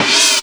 59 CYMB 1 -L.wav